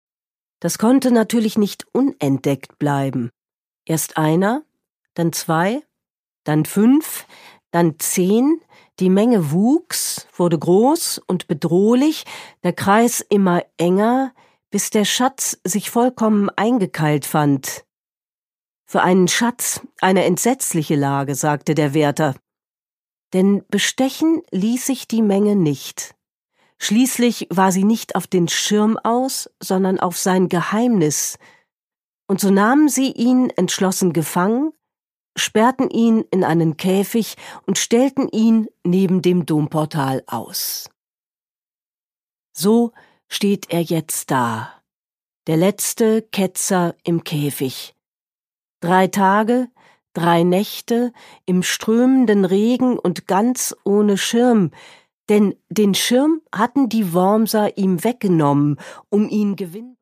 Produkttyp: Hörbuch-Download
Gelesen von: Felicitas Hoppe